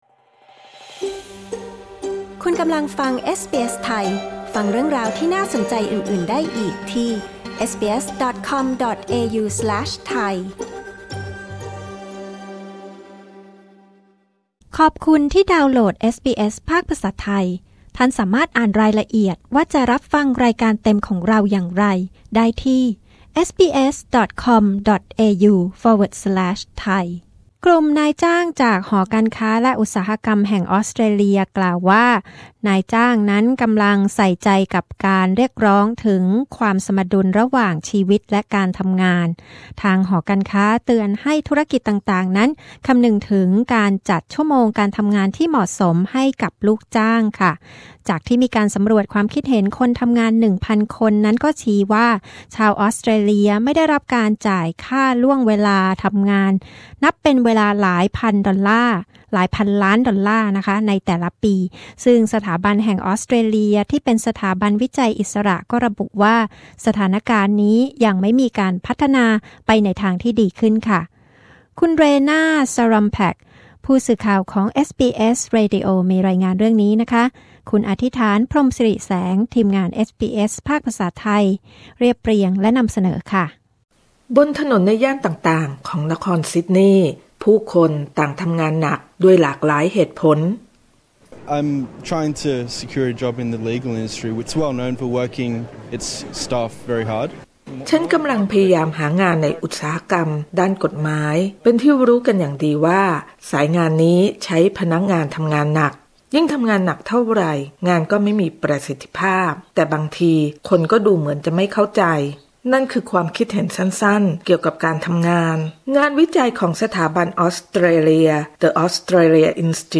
กดปุ่ม 🔊 ที่ภาพด้านบนเพื่อฟังรายงานเรื่องนี้